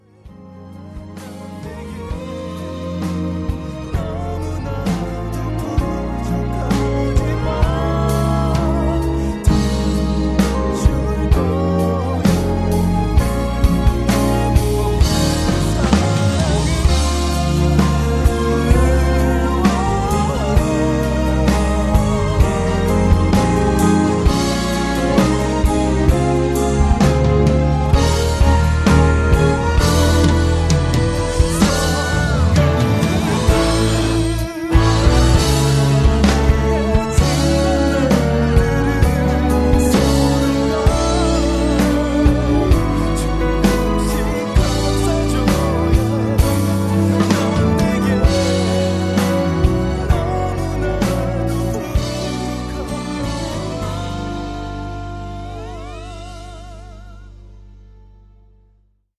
음정 -1키 4:24
장르 가요 구분 Voice MR